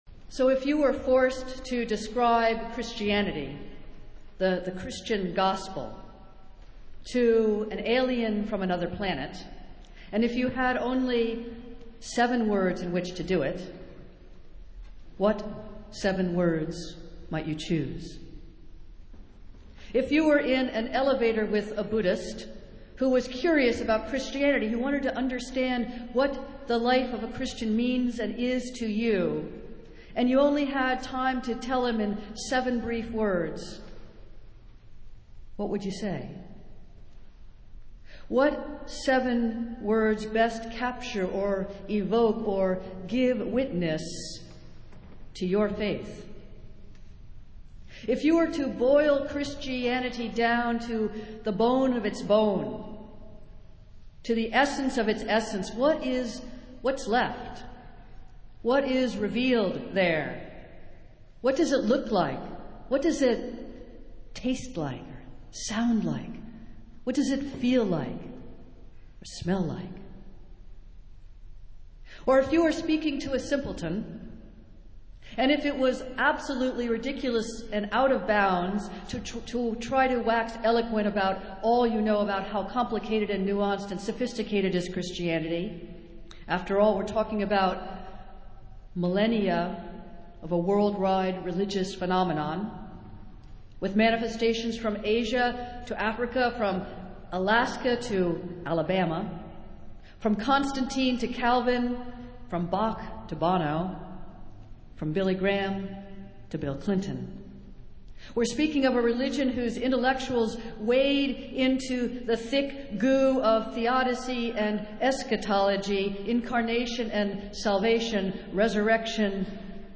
Festival Worship - Twentieth Sunday after Pentecost